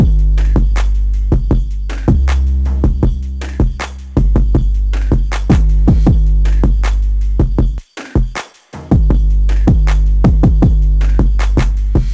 Dirty Phonk BIT Drum Loop Gm 158.ogg
Hard punchy kick sample for Memphis Phonk/ Hip Hop and Trap like sound.
.WAV .MP3 .OGG 0:00 / 0:12 Type Ogg Duration 0:12 Size 2,04 MB Samplerate 44100 Hz Bitrate 499 kbps Channels Stereo Hard punchy kick sample for Memphis Phonk/ Hip Hop and Trap like sound.